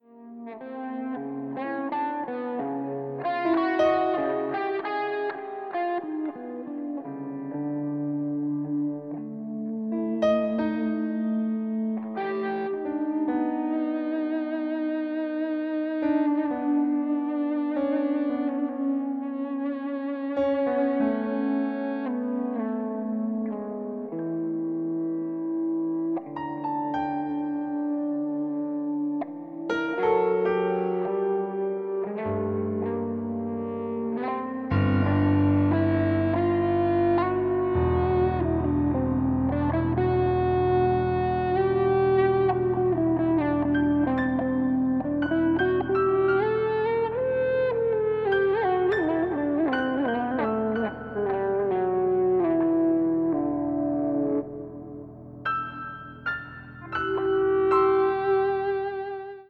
Electric guitar, Soundscapes, Live performance electronics